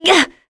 Talisha-Vox_Attack6.wav